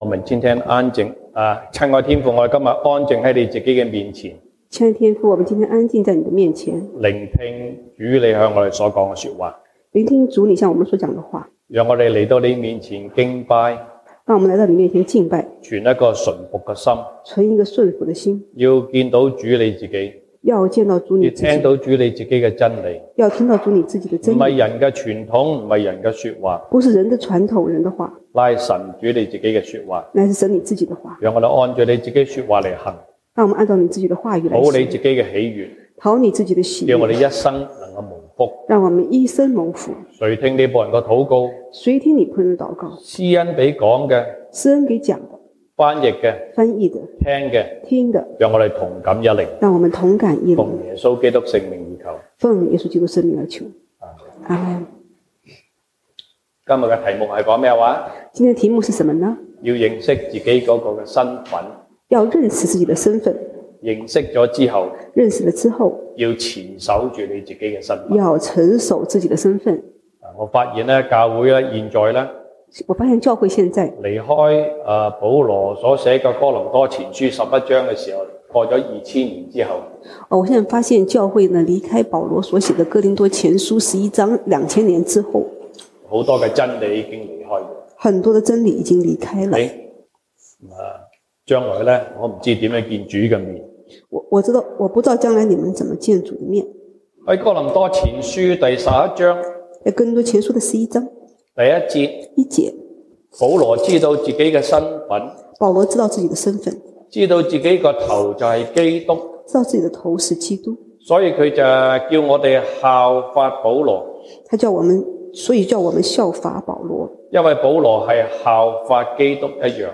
西堂證道(粵語/國語) Sunday Service Chinese: 要認識和持守你自己的身份